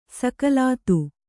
♪ sakalātu